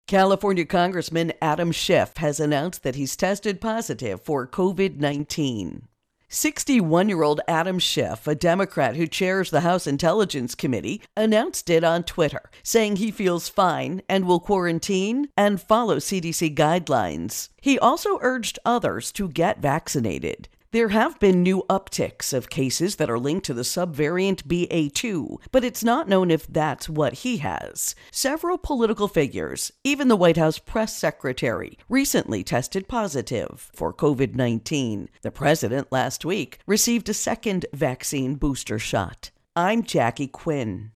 Virus Outbreak Adam Schiff Intro and Voicer